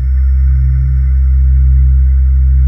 Index of /90_sSampleCDs/USB Soundscan vol.28 - Choir Acoustic & Synth [AKAI] 1CD/Partition C/05-ANGEAILES